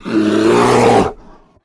Kodiak Bear Roar and Breath, Low To High Pitch; Exterior